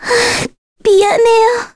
Ophelia-Vox_Dead_kr.wav